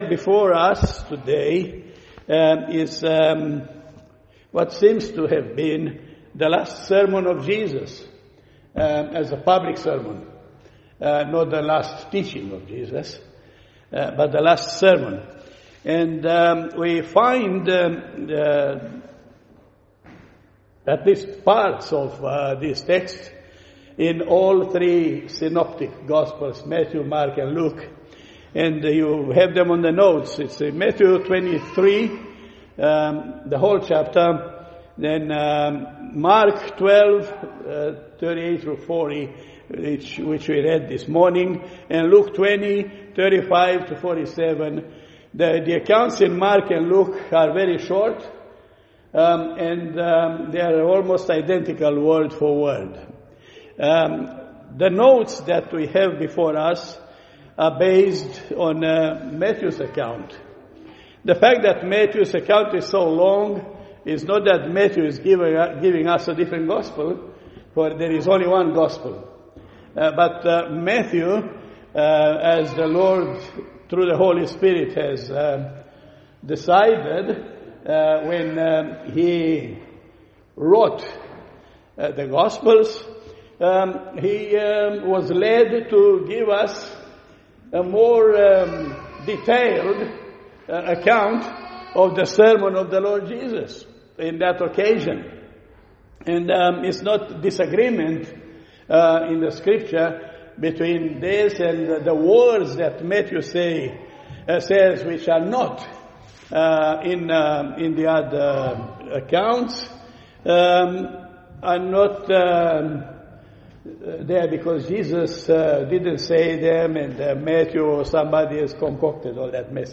Harmony of the Gospels Passage: Matthew 23:1-12 Service Type: Sunday Morning « God’s Prescribed Way of Worship